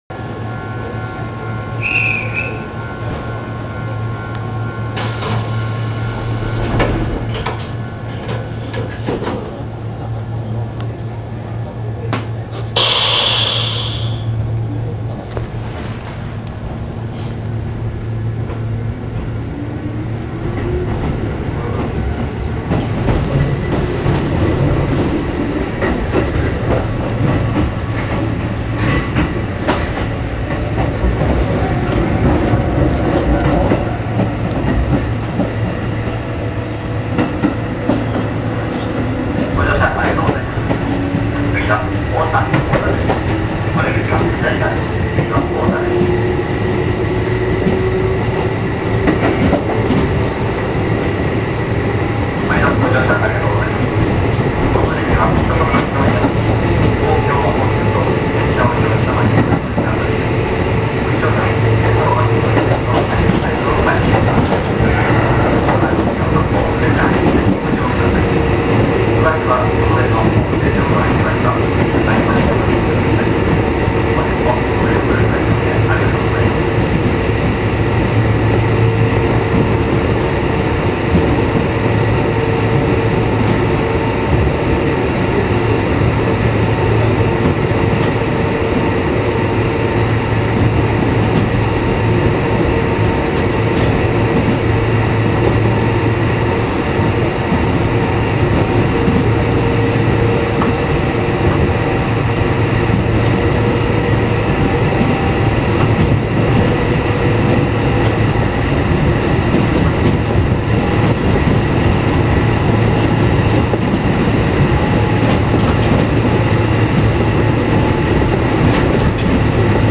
〜車両の音〜
・1080形走行音
【琴平線】三条→太田（3分33秒：1.13MB）…1083にて。
なんてことのない、京急1000形の音。